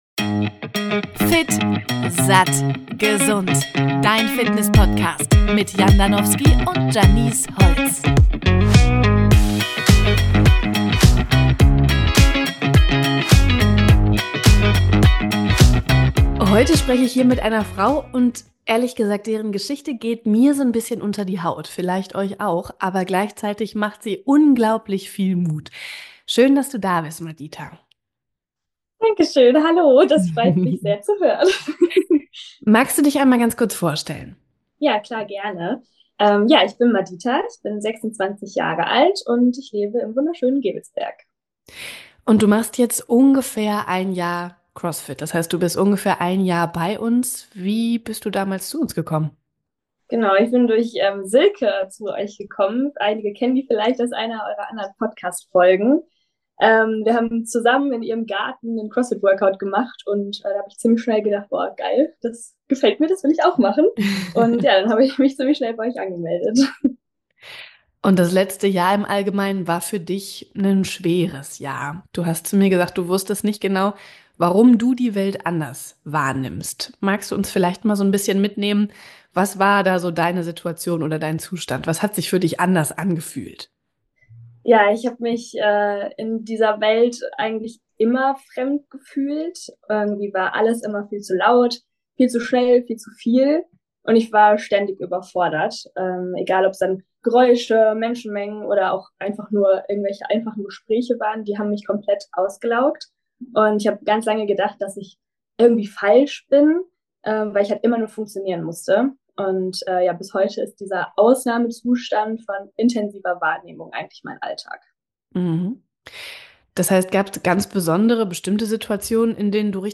Ein Gespräch über Heilung, Gewichtszunahme, mentale Gesundheit – und darüber, wie ein Ort und eine Gemeinschaft manchmal mehr bewirken können als tausend Therapiegespräche.